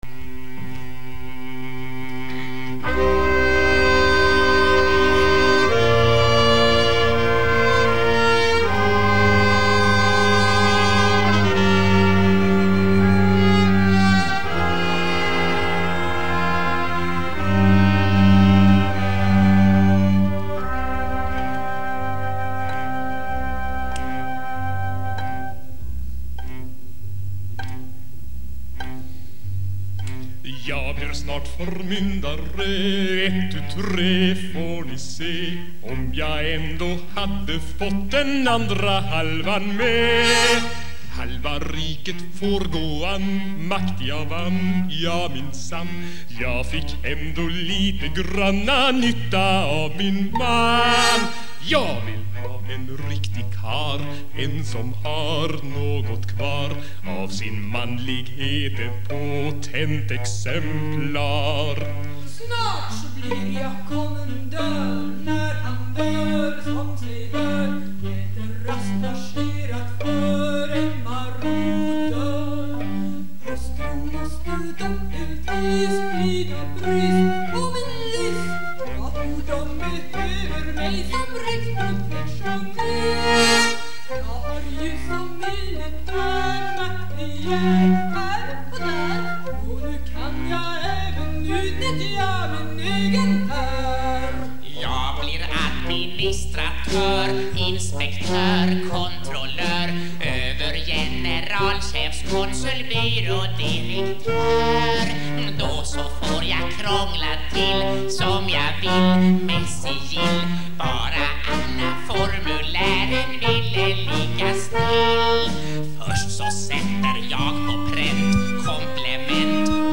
Låtar som av någon anledning valts ut från spex-86.